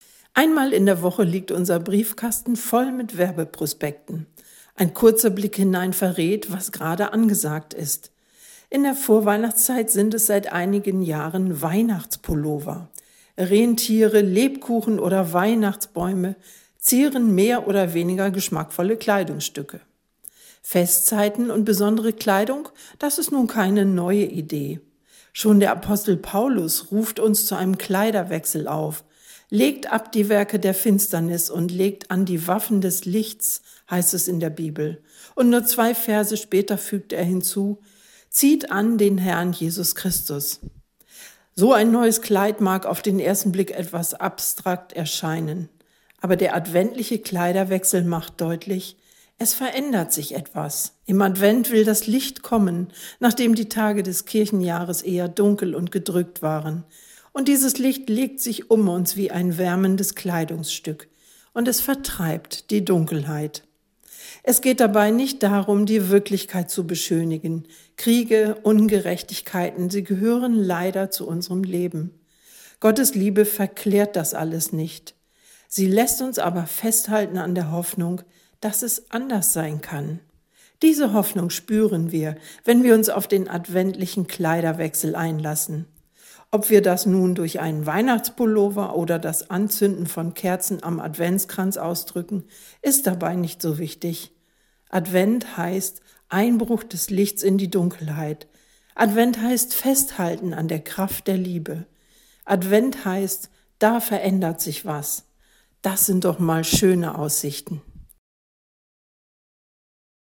Radioandacht vom 15. Dezember